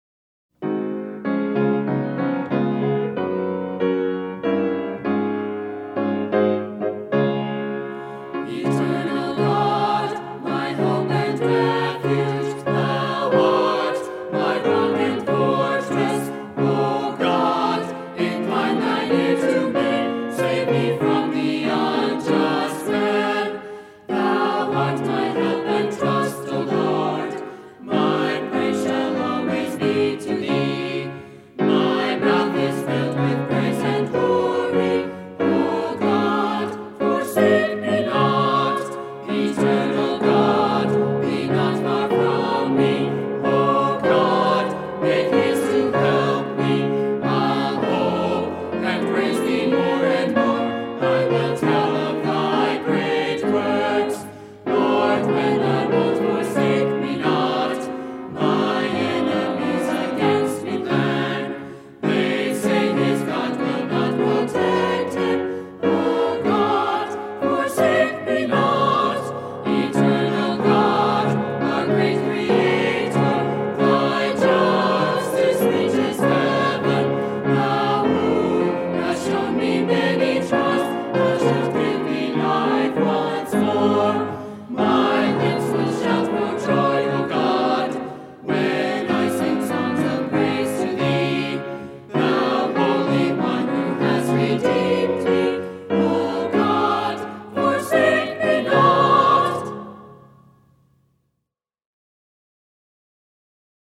With Vocals